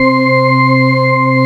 HAMMOND  1.wav